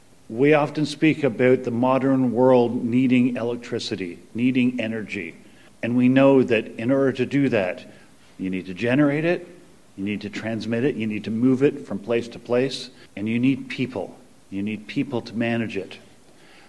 The Province of Ontario announced a major increase to the Indigenous Energy Support Program on Tuesday morning.